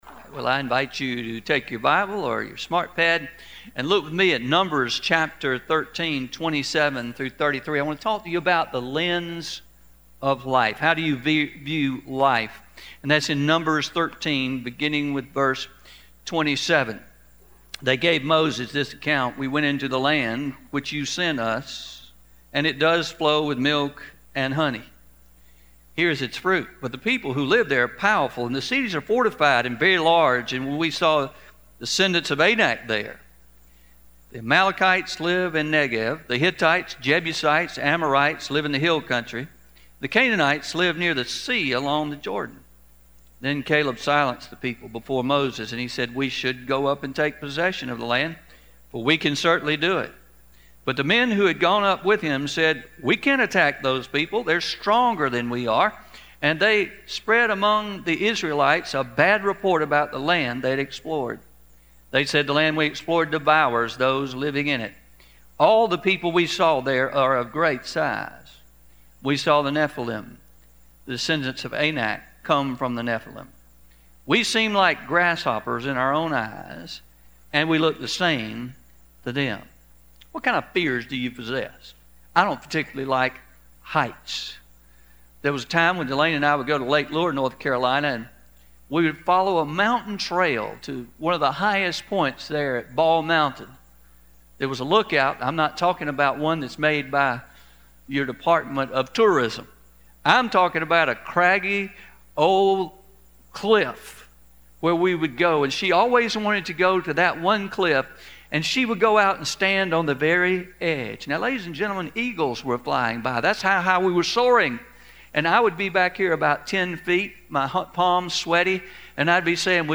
Sermon – The Lens of Life – (Pre-Recorded)